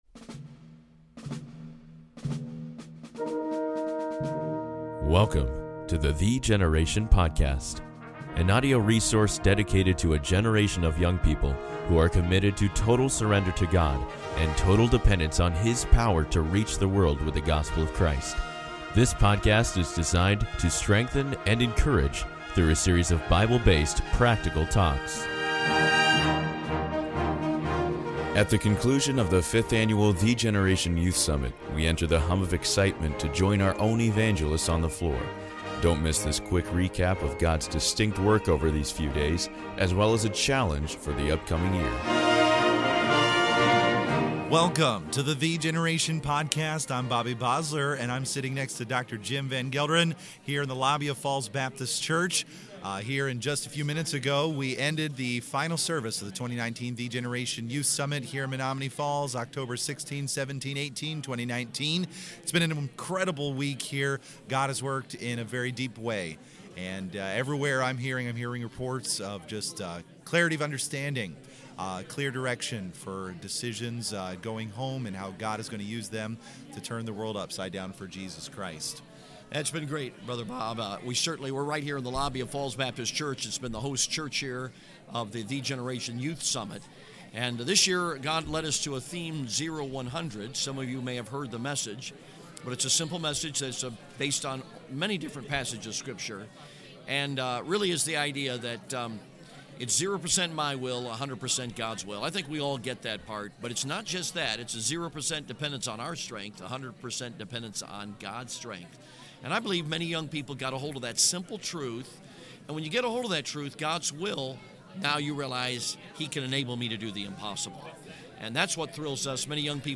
At the conclusion of the fifth annual Thee Generation Youth Summit, we enter the hum of excitement to join our own evangelists on the floor. Don’t miss this quick recap of God’s distinct work over these few days, as well as a challenge for the upcoming year.